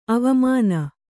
♪ avamāna